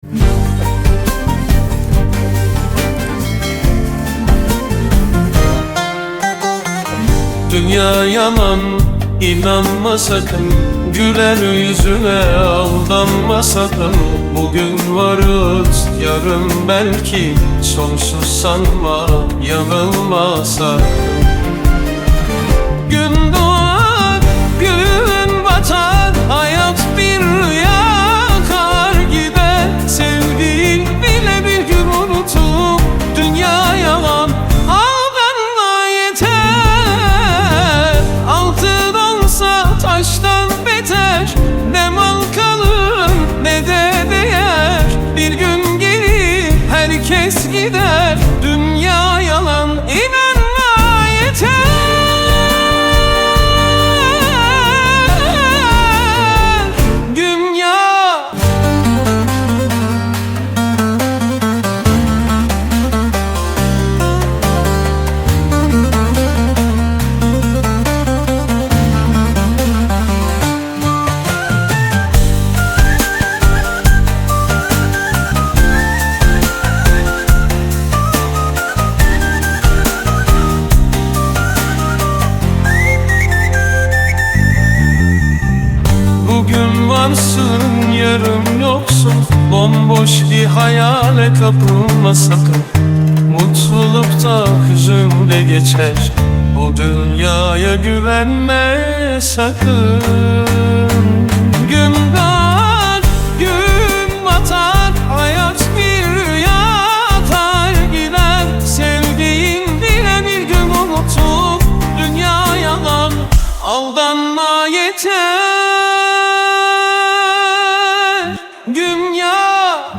Yapay zeka